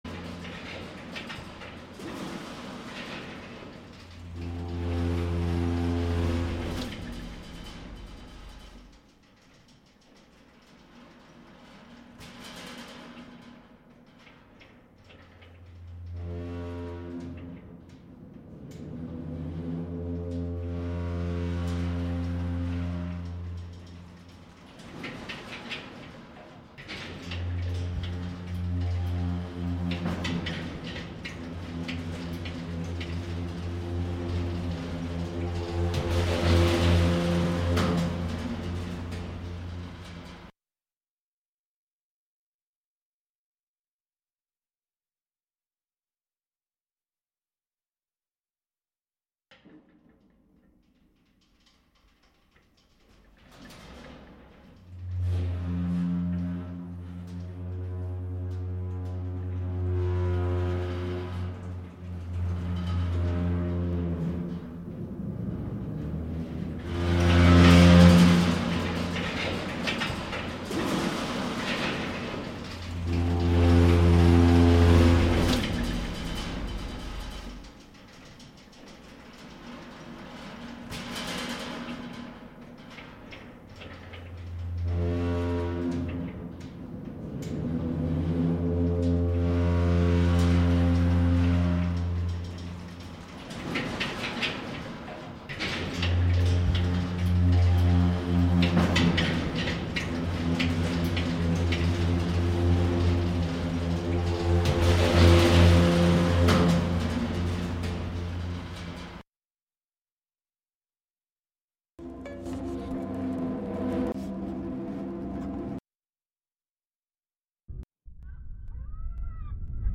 Halffloor live from Fries TV, Ebertplatz. 18:00-20:00 (CET, Cologne, local time) Play In New Tab (audio/mpeg) Download (audio/mpeg)